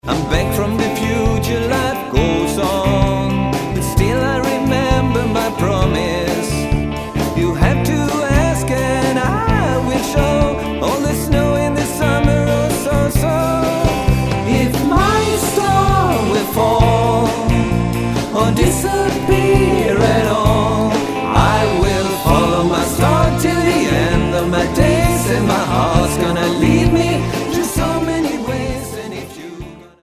Vinsten var att få spela in låtarna i studio.
Fullfjädrat coverband.